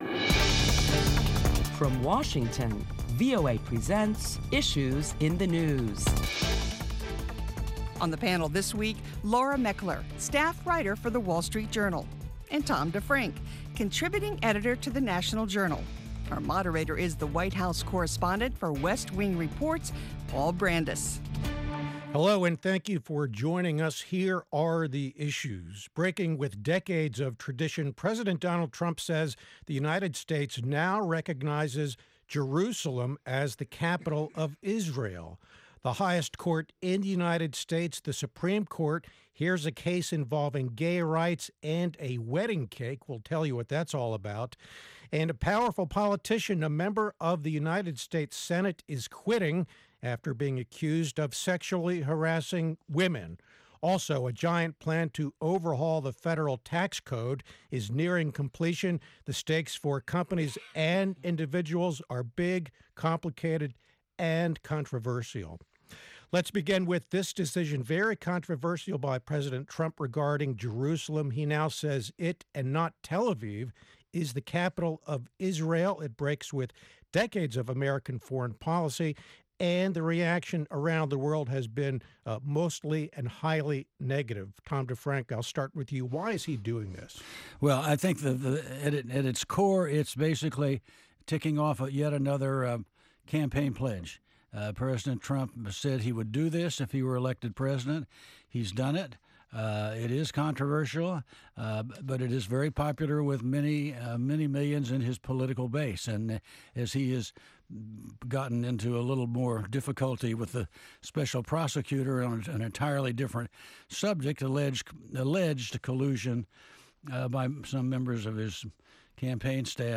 Prominent Washington correspondents discuss topics making headlines around the world including President Trump's announcement that the U.S. would officially recognize Jerusalem as the capital of Israel.